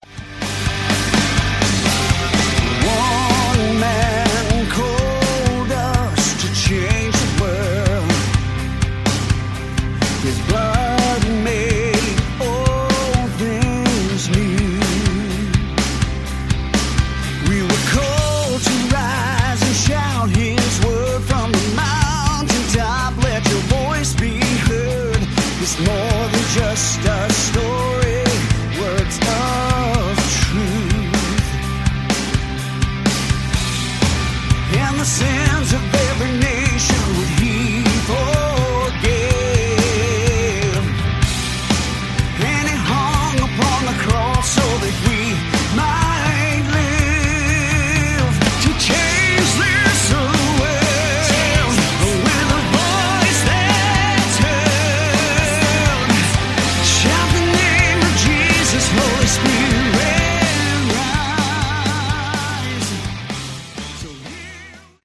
Category: Hard Rock
lead vocals, keyboards
guitars, vocals
drums
bass